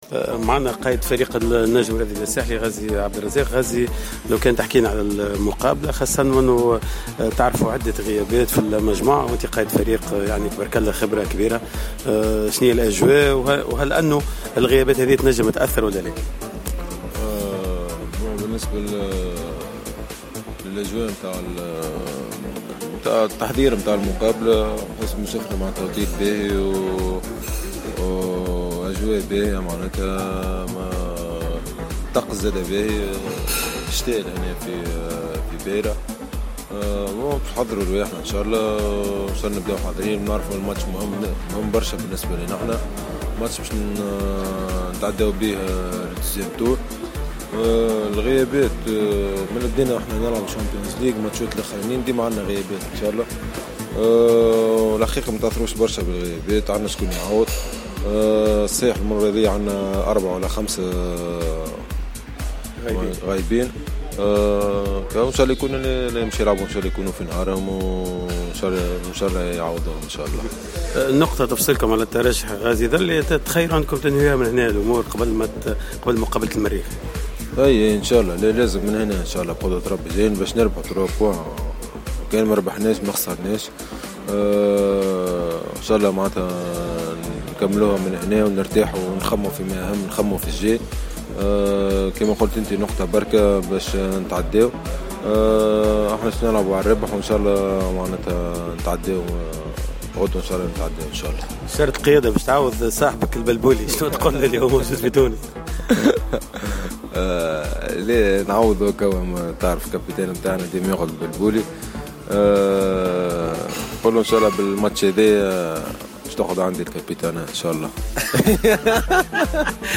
و في دردشة طريفة ,اكد الظهير الايسر للنجم انه سيفتك شارة القيادة من البلبولي .